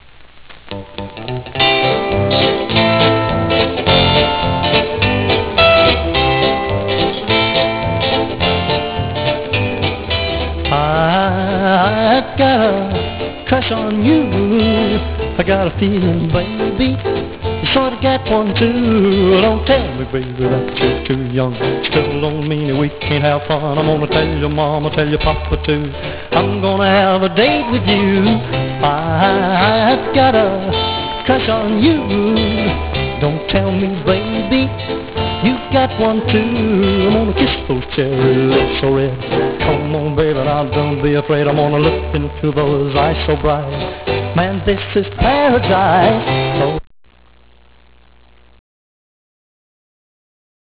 Garage Rock WAV Files